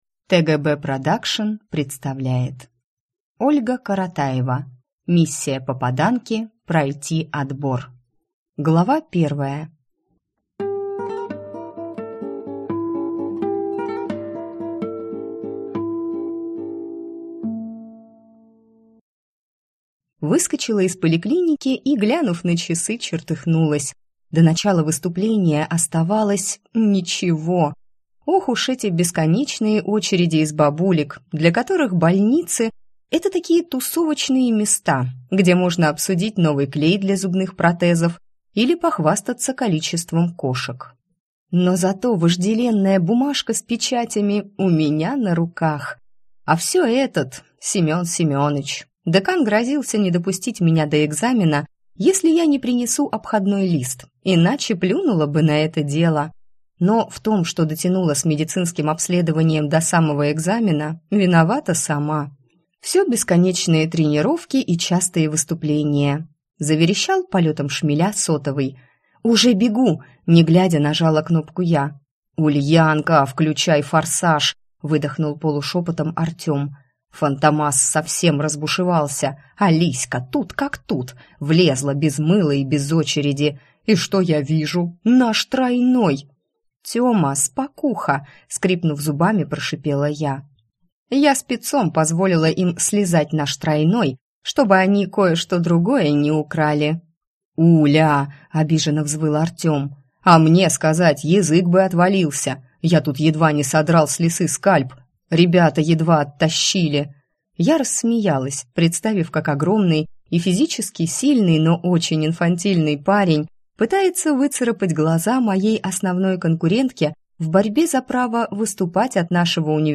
Аудиокнига Миссия попаданки: пройти отбор!